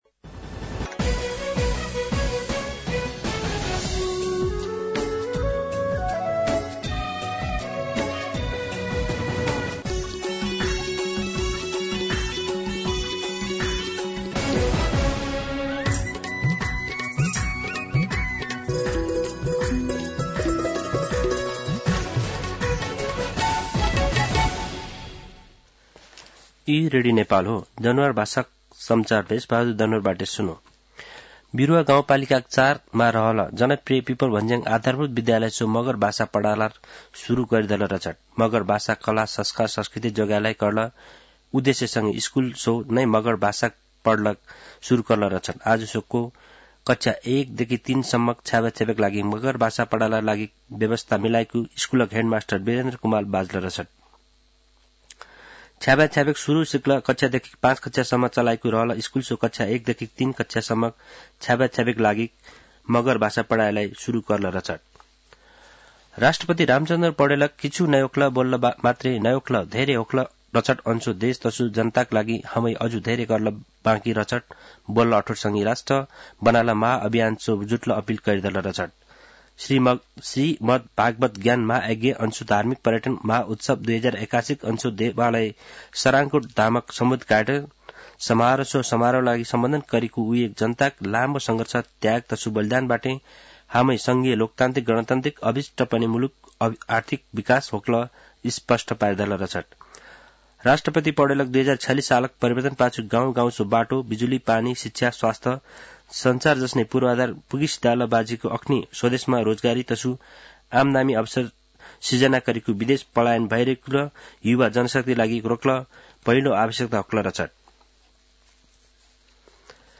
An online outlet of Nepal's national radio broadcaster
दनुवार भाषामा समाचार : १८ फागुन , २०८१
Danuwar-News-11-17.mp3-.mp3